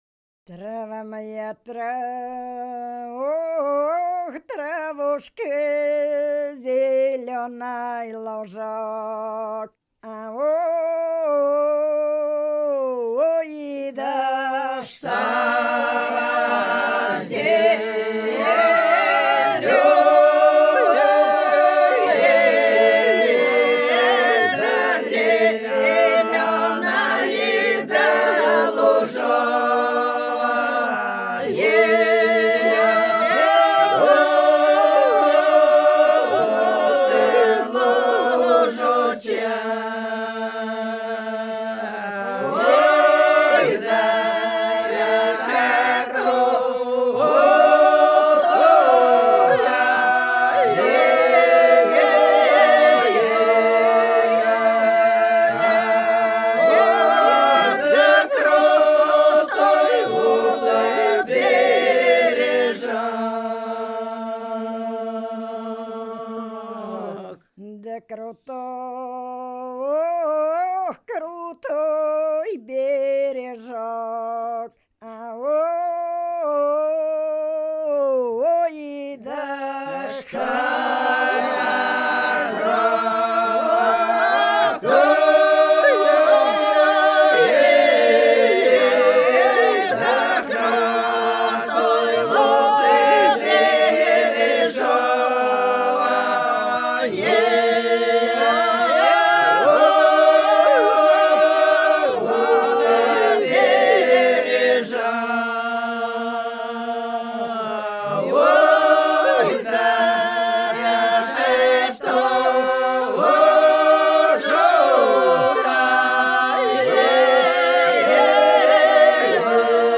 Ансамбль села Хмелевого Белгородской области Трава моя, травушка, зеленый лужок (стяжная, преимущественно весной)